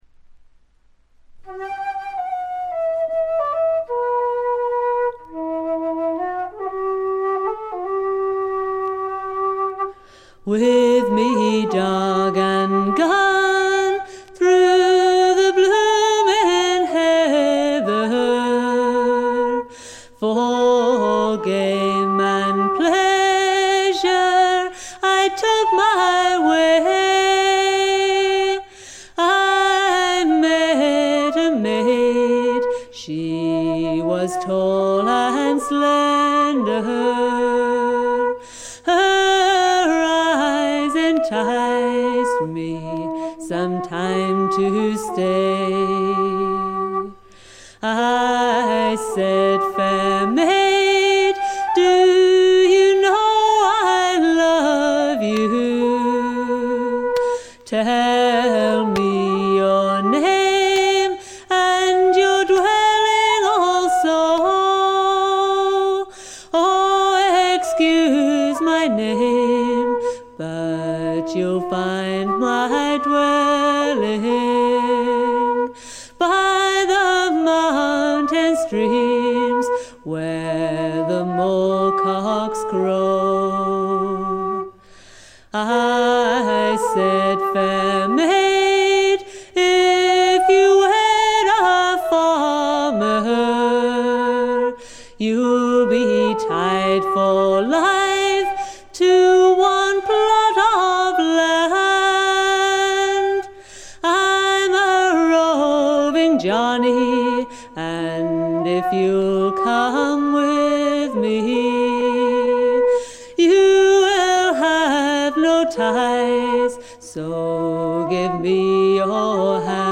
B5序盤「ザッ」というノイズが5回ほど。
試聴曲は現品からの取り込み音源です。
Vocals
Guitar, Flute